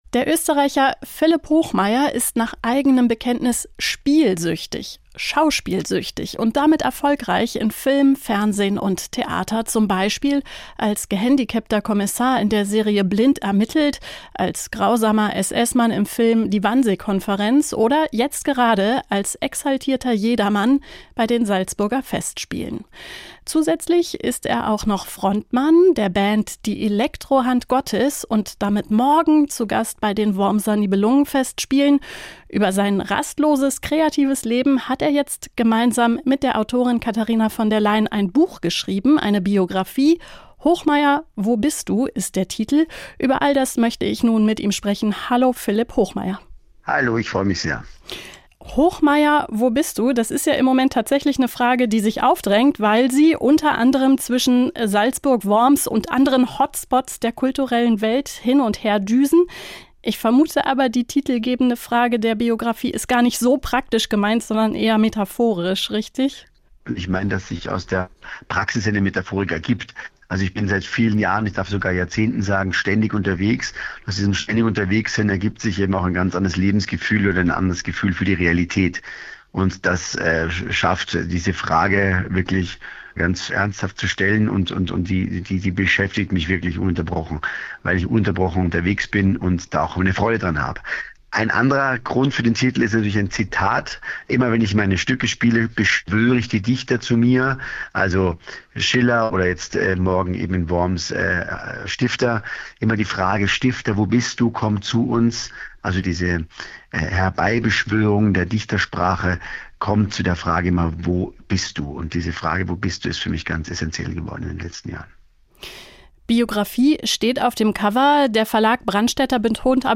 Spielsüchtig auf Bühne und Leinwand: Philipp Hochmair im Gespräch